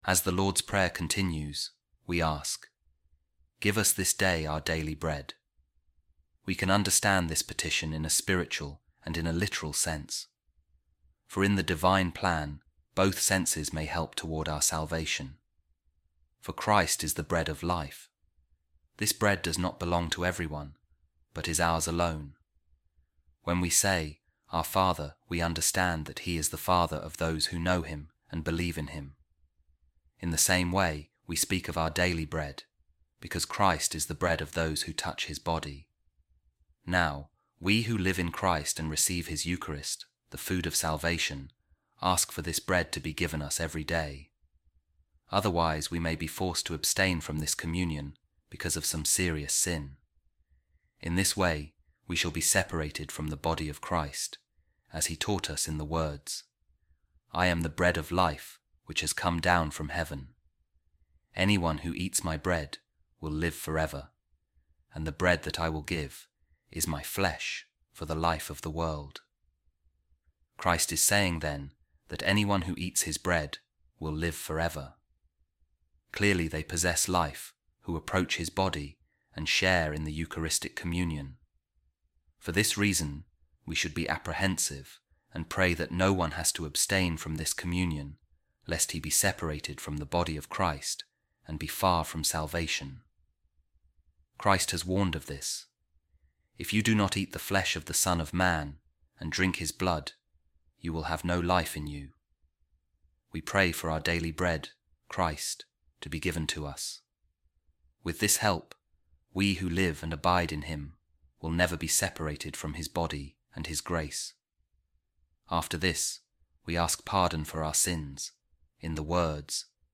A Reading From The Treatise Of Saint Cyprian On The Lord’s Prayer | We Ask For Bread And Then For Forgiveness Of Sins